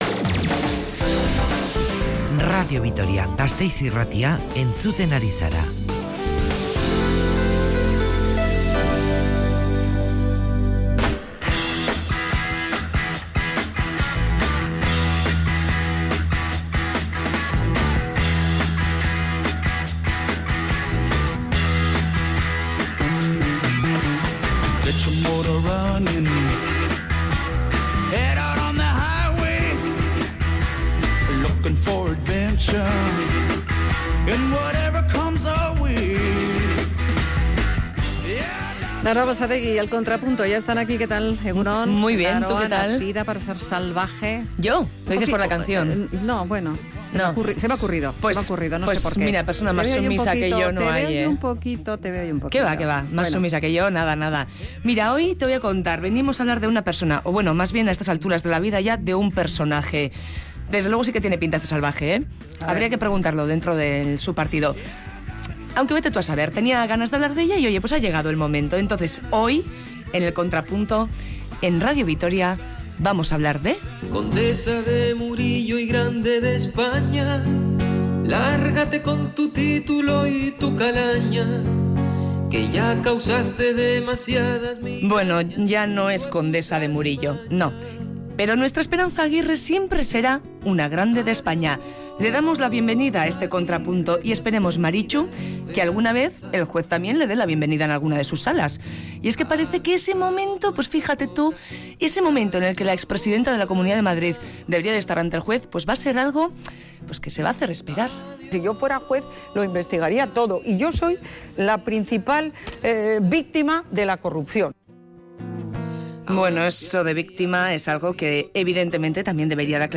Audio: Recopilación de las frases de Esperanza Aguirre por tramas de corrupción en El Contrapunto, Araba Gaur 1. Radio Vitoria